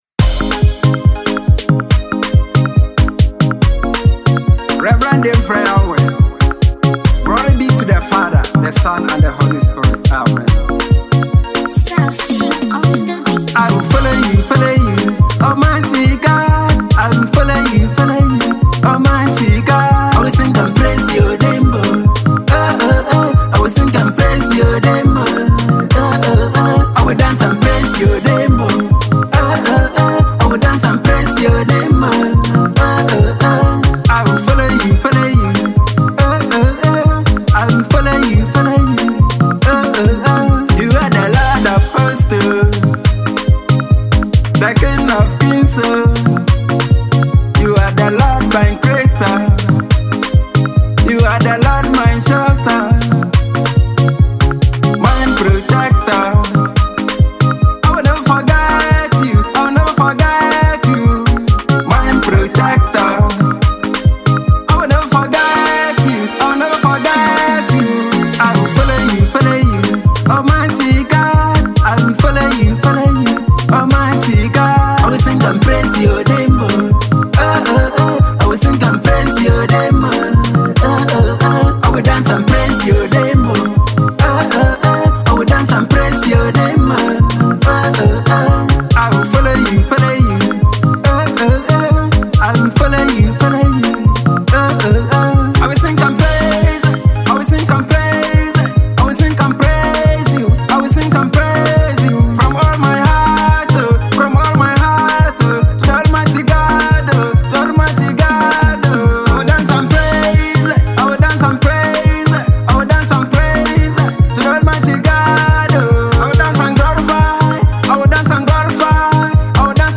Praises